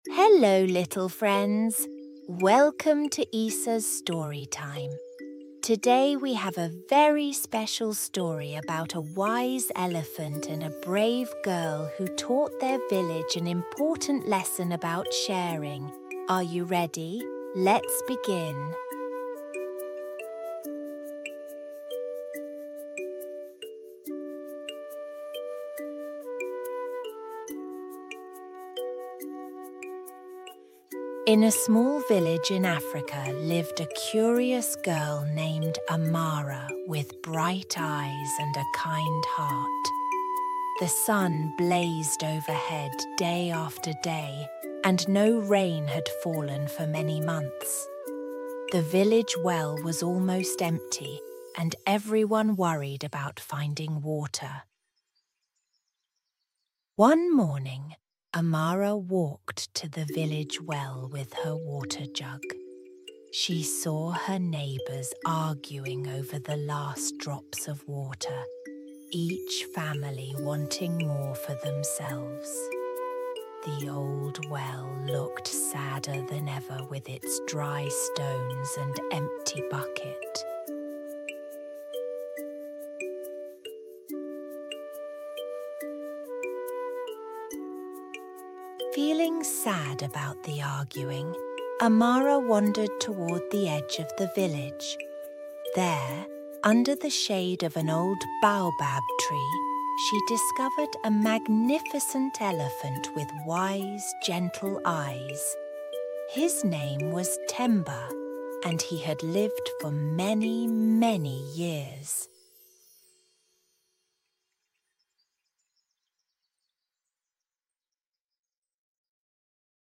Cuento en Audio